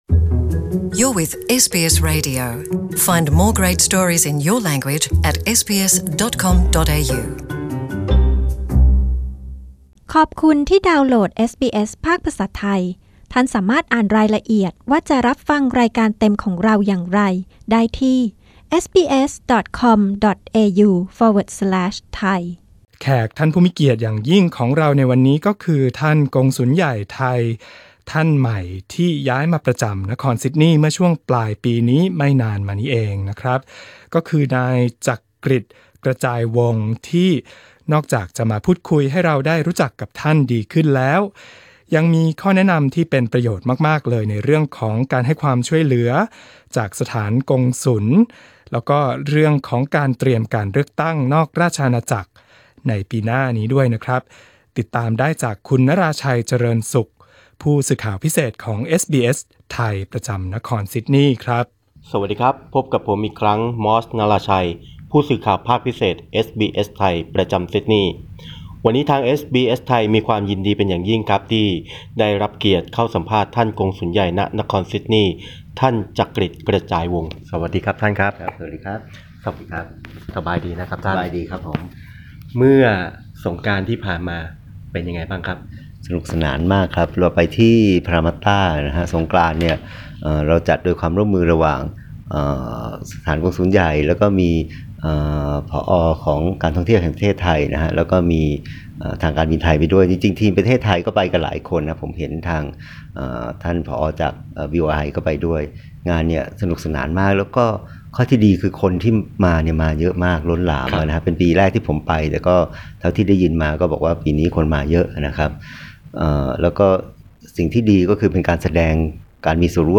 เอสบีเอส ไทย พูดคุยกับนายจักรกฤดิ กระจายวงศ์ กงสุลใหญ่ท่านใหม่ซึ่งย้ายมาประจำการ ณ นครซิดนีย์เมื่อช่วงปลายปีนี้ ว่าชาวไทยในออสเตรเลียควรเริ่มเตรียมพร้อมสำหรับการเลือกตั้งนอกราชอาณาจักรในปีหน้าอย่างไร นอกจากนั้นท่านยังอธิบายว่ามีความช่วยเหลืออะไรบ้าง ที่สถานกงสุลมีไว้บริการชุมชนไทย